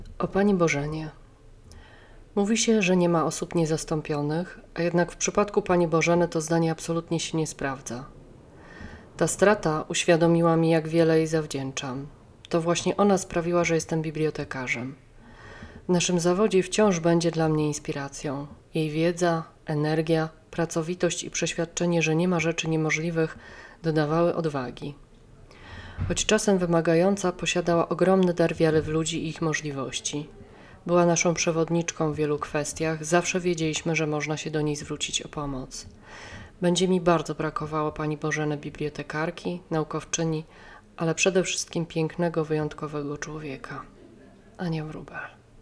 Typ Historia mówiona